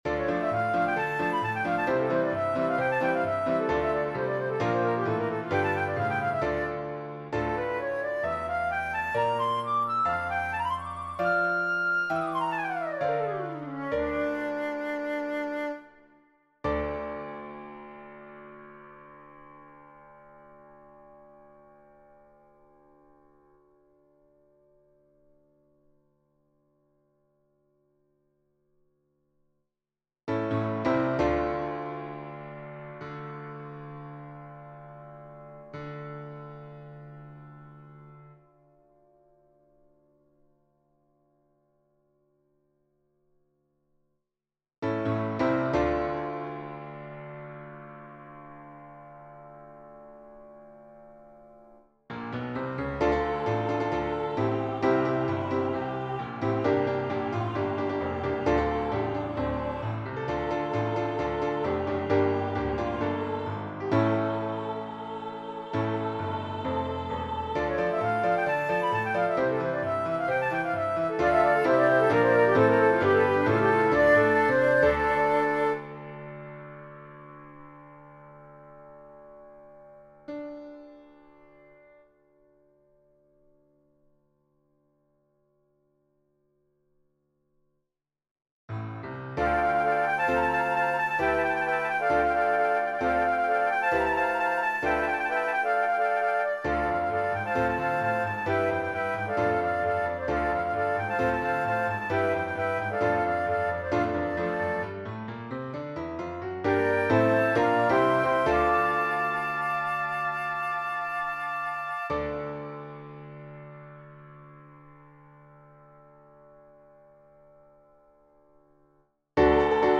The song is written in the key of D minor.